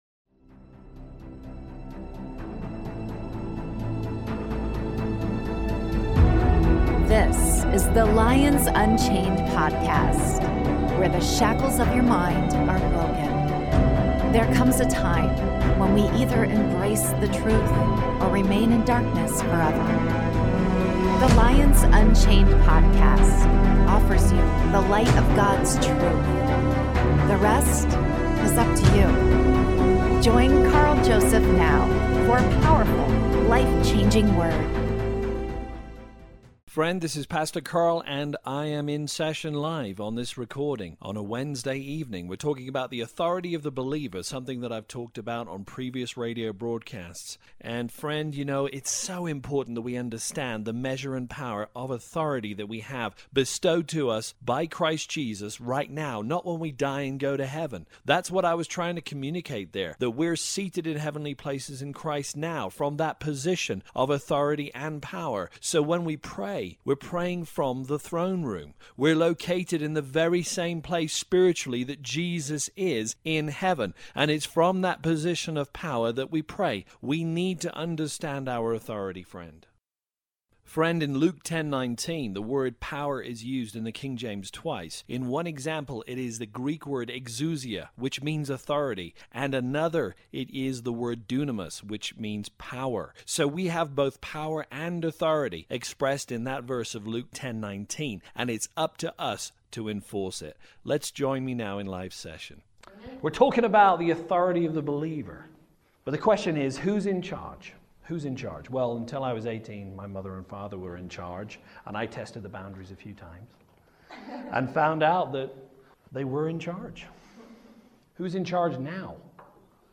Knowing our authority in Christ is perhaps the most important aspect of Christendom, after salvation. In this live broadcast